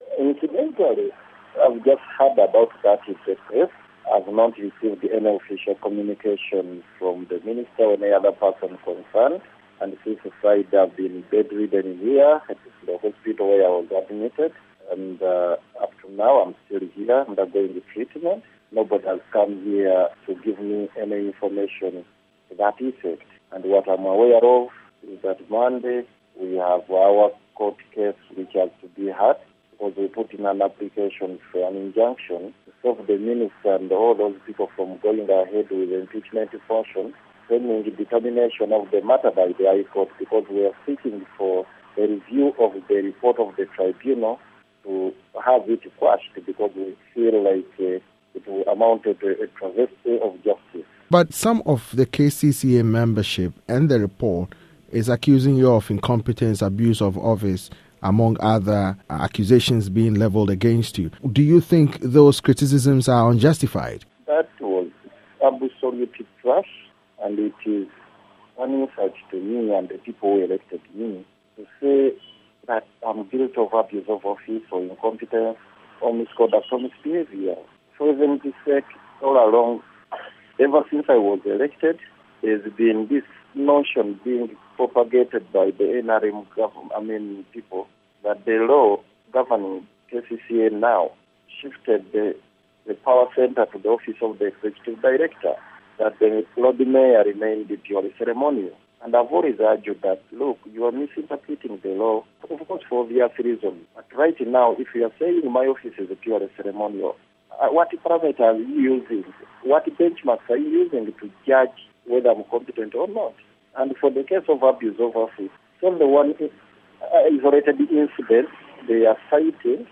interview with Erias Lukwago, Lord Mayor of Kampala